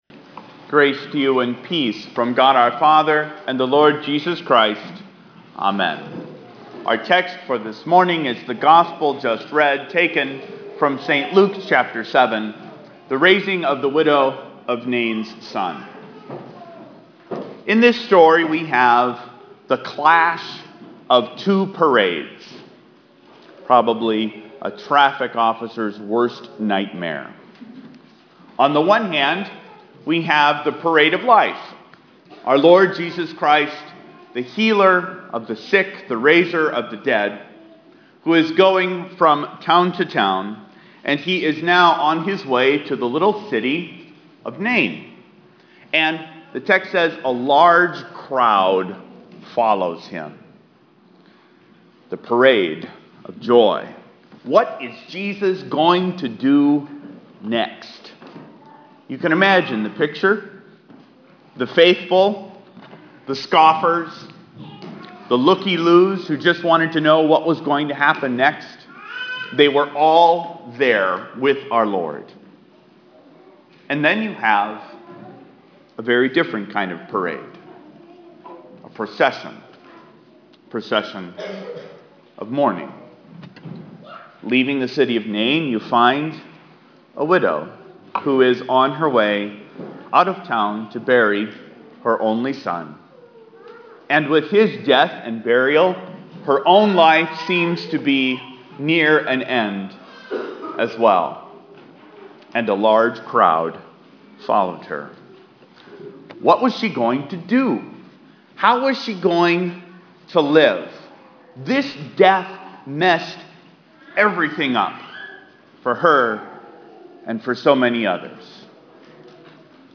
Here’s the audio for my Trinity 16 sermon on the raising of the widow of Nain’s Son.